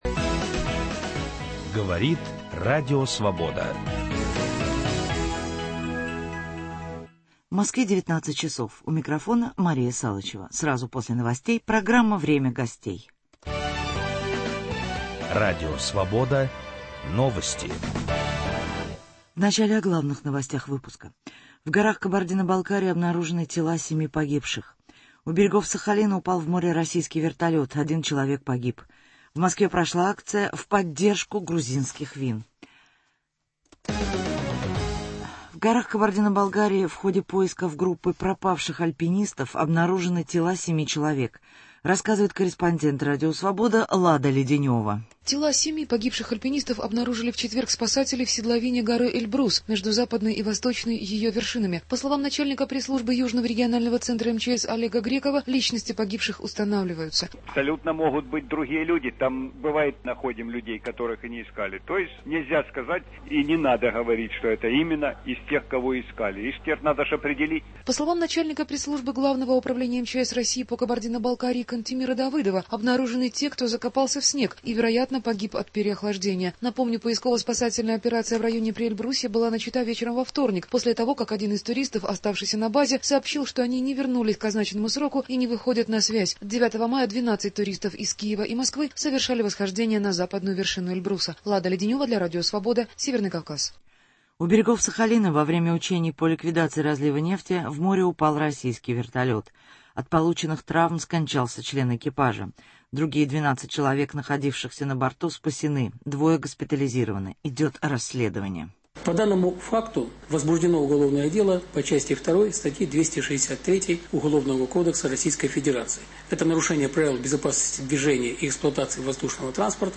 О проблемах села, о земле, о национальных проектах, о многом другом - в беседе с губернатором Ленинградской области Валерием Сердюковым.